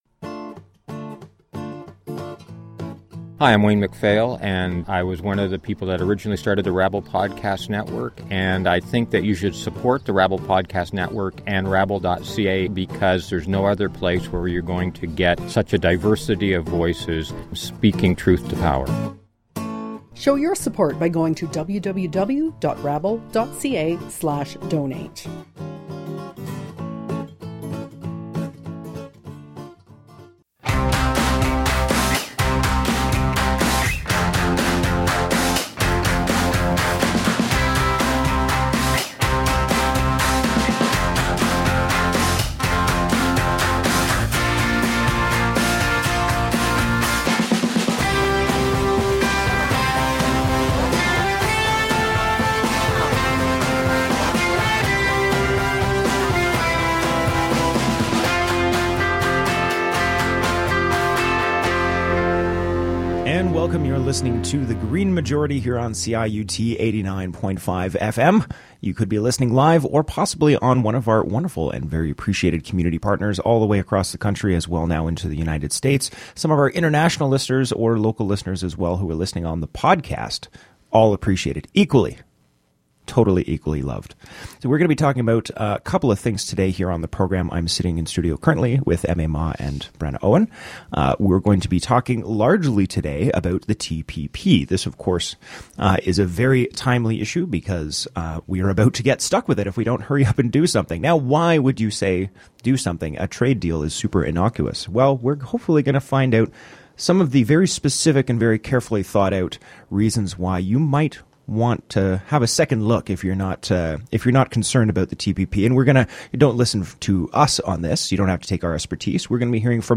Genre: Eco-News + Interviews
The final section of the show, and the entire bonus show is dedicated to our crack team of hosts talking from our perspectives (with examples) of why you should be loudly opposed to the TPP.